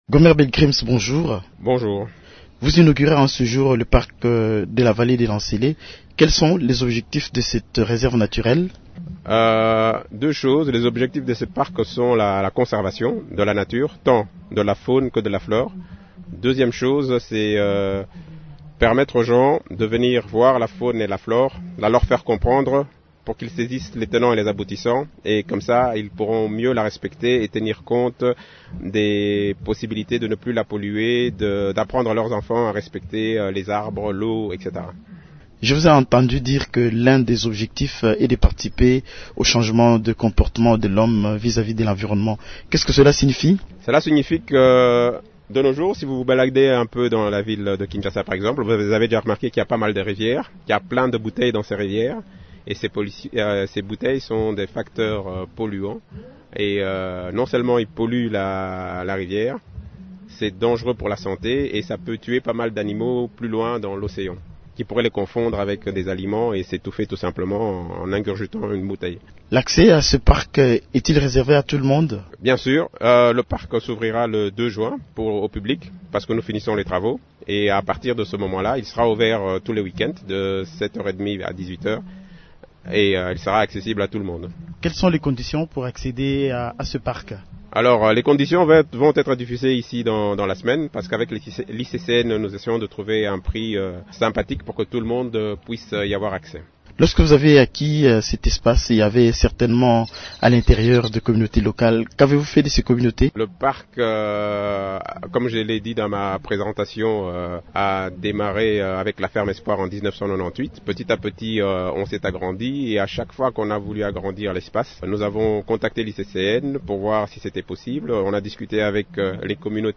Invité de Radio Okapi